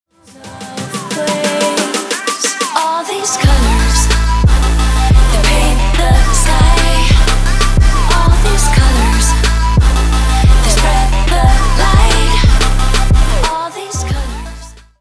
From Dubstep to Bass House, Future Bass and Trap!